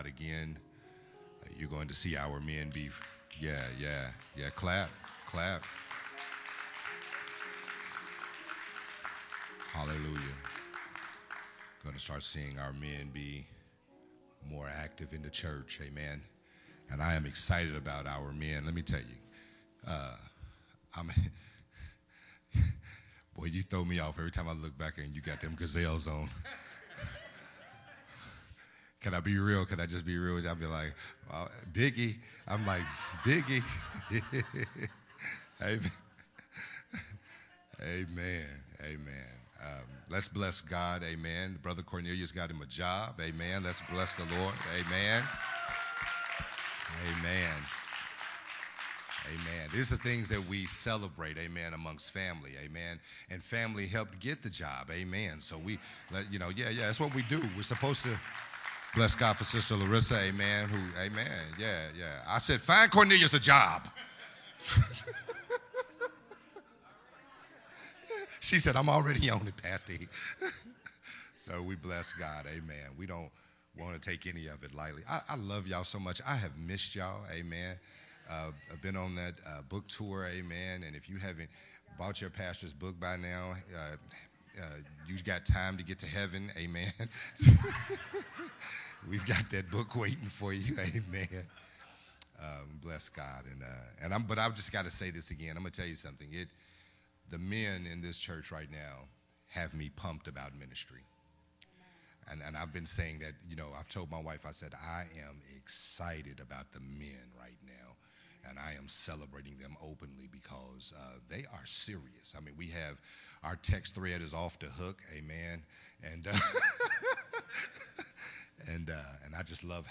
Part 1 of a new sermon series
recorded at Unity Worship Center on Sunday, October 2, 2022.